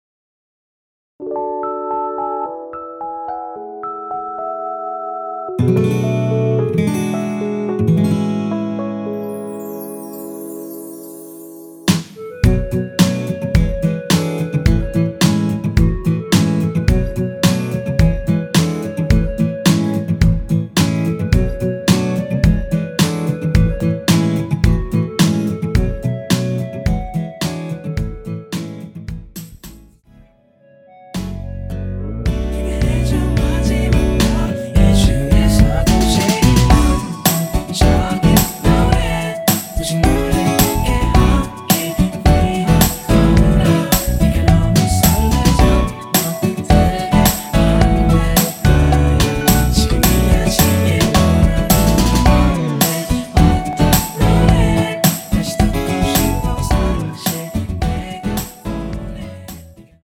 원키 멜로디와 코러스 포함된 MR입니다.(미리듣기 확인)
앞부분30초, 뒷부분30초씩 편집해서 올려 드리고 있습니다.
중간에 음이 끈어지고 다시 나오는 이유는